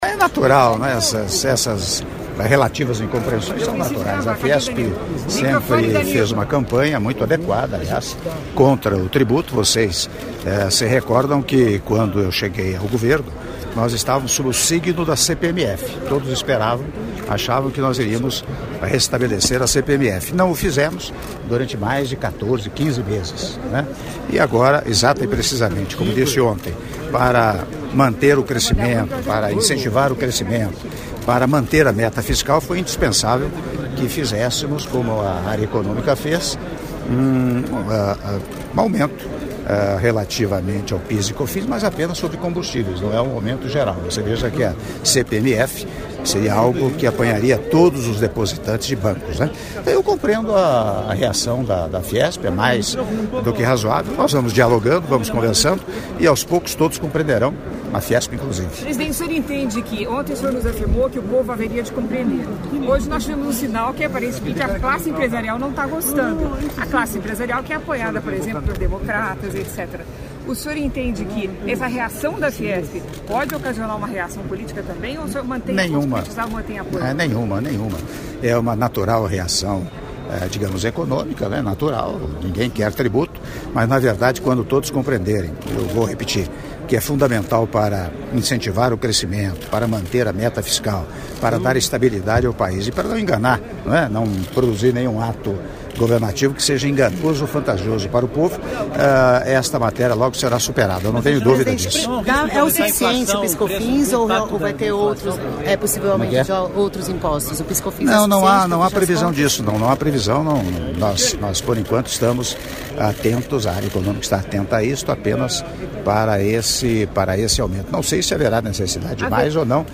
Áudio da entrevista do Presidente da República, Michel Temer, concedida após Sessão Plenária dos Senhores Presidentes dos Estados Membros do Mercosul, Estados Associados, México e Convidados Especiais - Mendoza/Argentina - (03min30s)